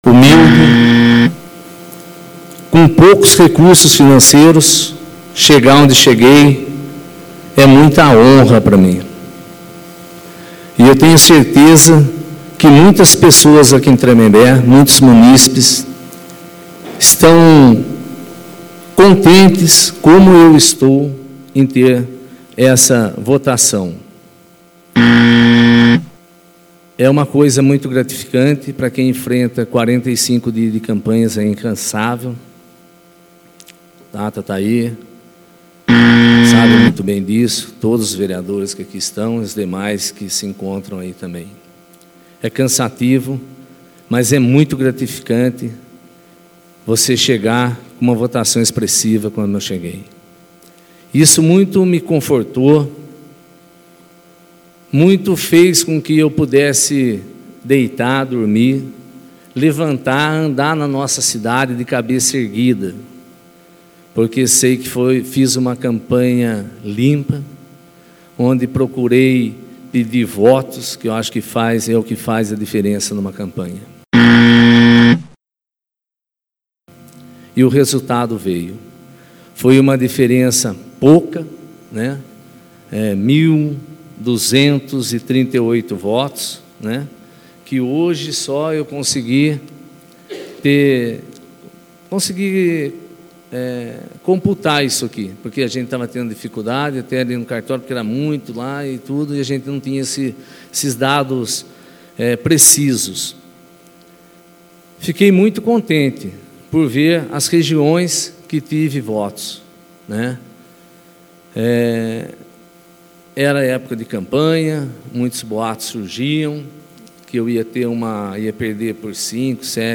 OBSERVAÇÃO: Devido a falhas na gravação da sessão alguns trechos estão inaudíveis.